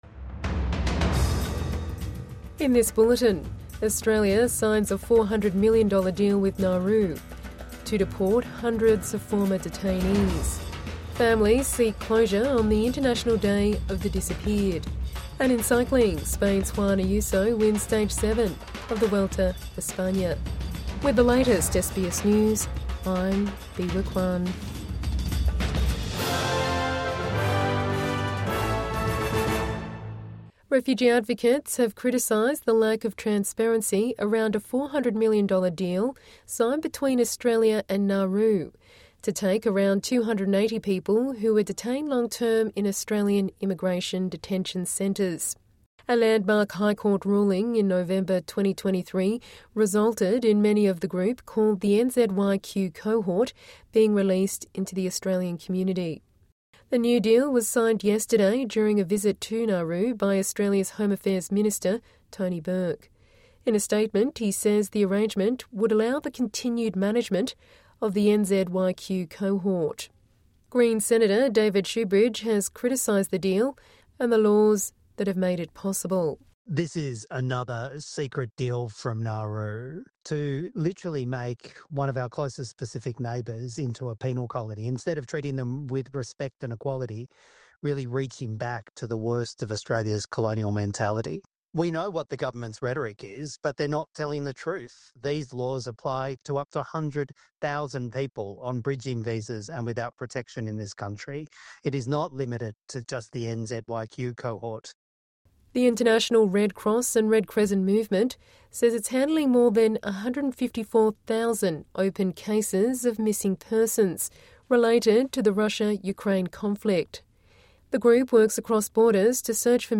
Evening News Bulletin 30 August 2025